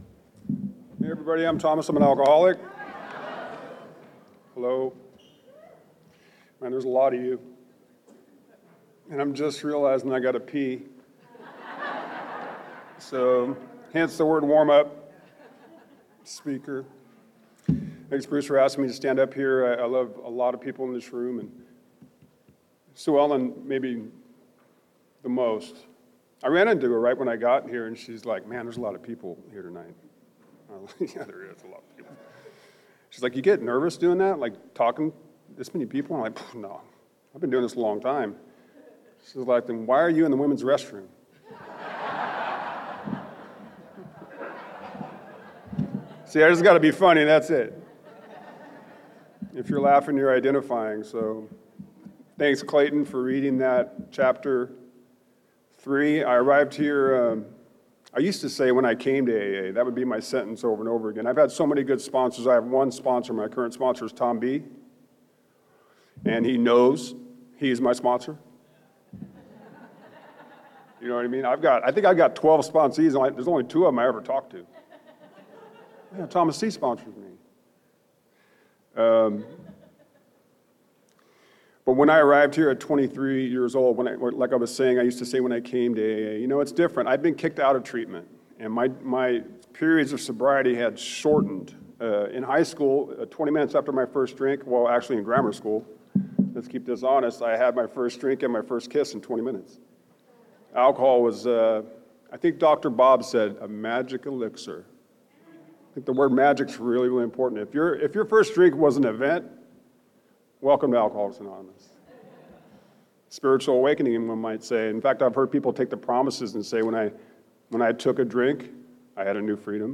17th Annual Sponsor Dinner - Fresno CA